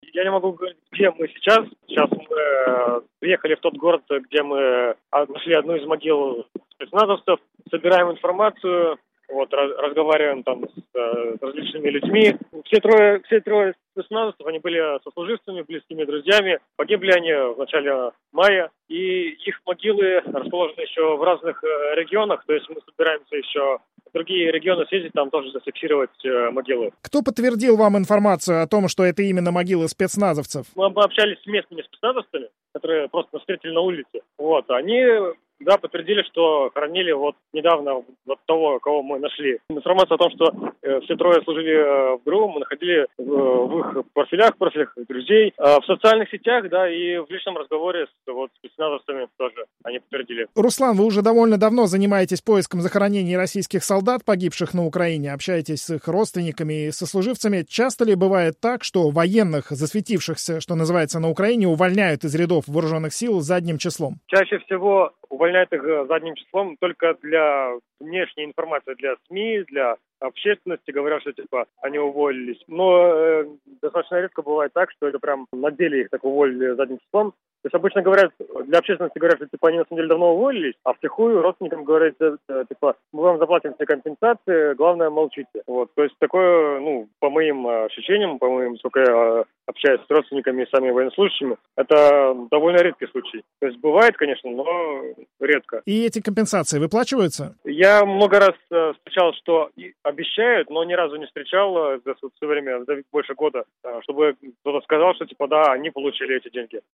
В разговоре с Радио Свобода